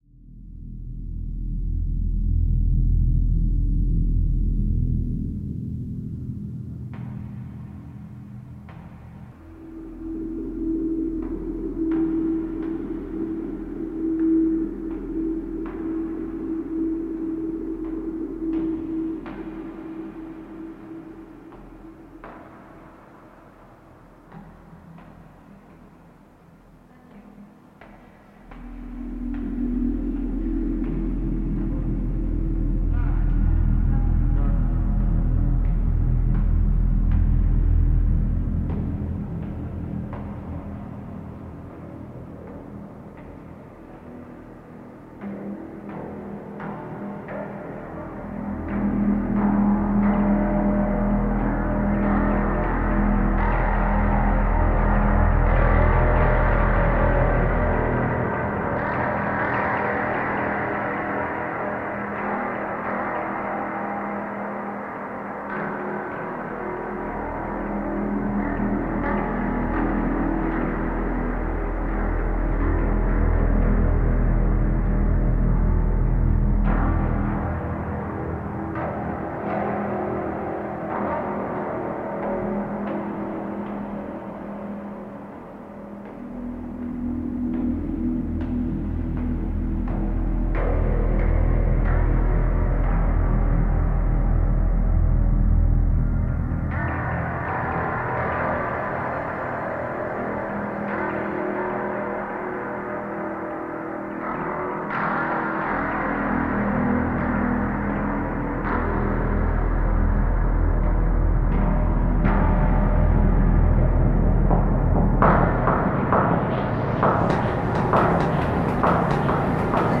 The stairs inside the Duomo of Milan, reimagined